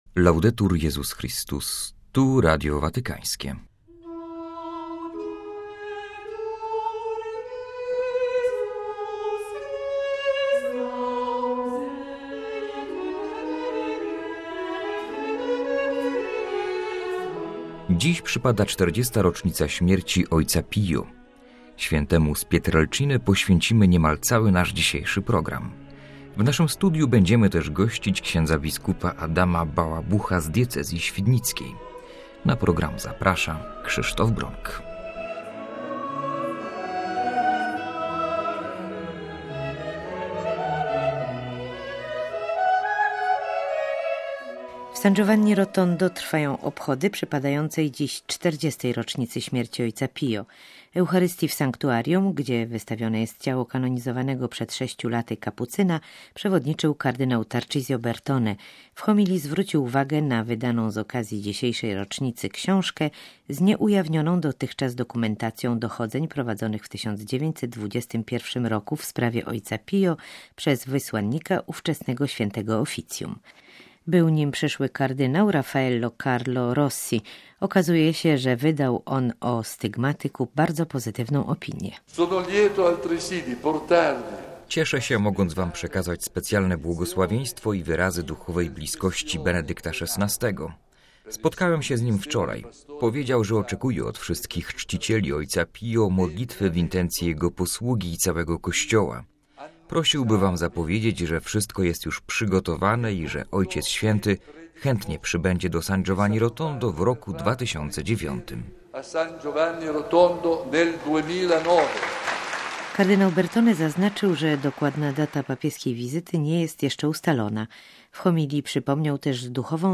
- rozmowa z bp. Adamem Bałabuchem.